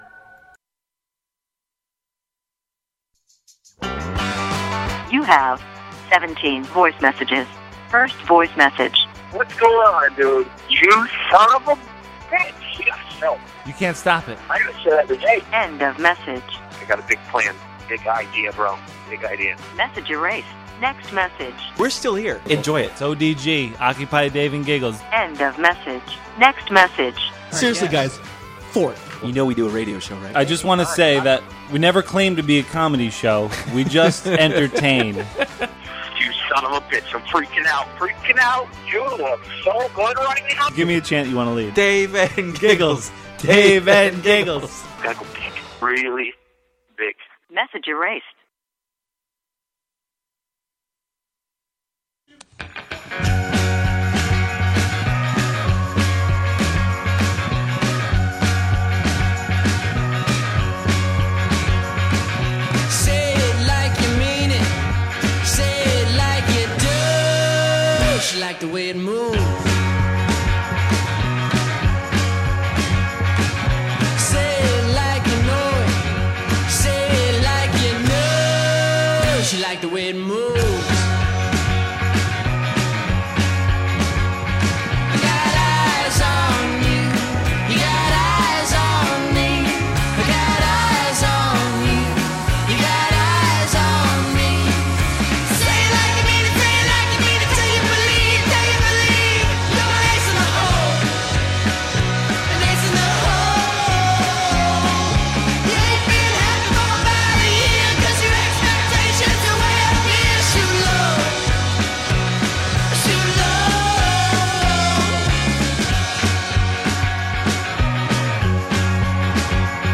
we have guests, and sometimes just ourself and a caller from time to time.